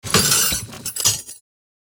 Clashing, Clanging and Resonating, from squeaky gates to hard metal impacts!
Metallic-hand-tool-drop-impact-3.mp3